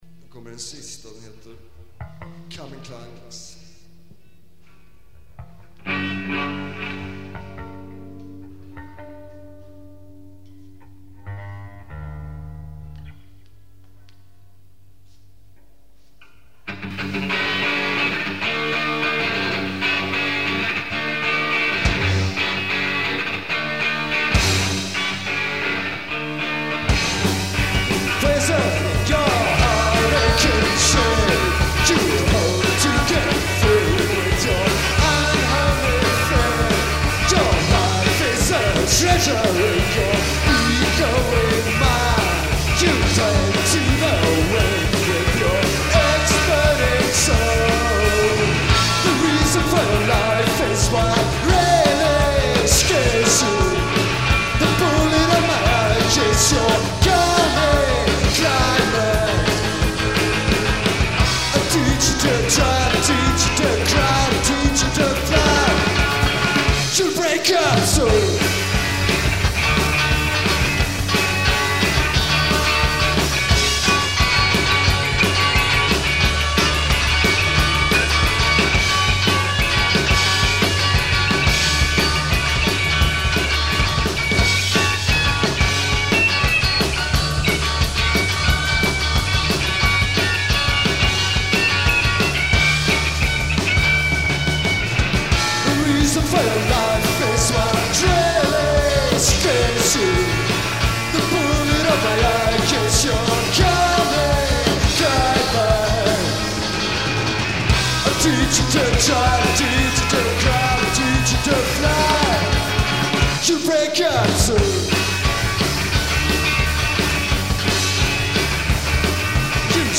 Bass
Guitar
Drums
At Arbis, November 9, 1983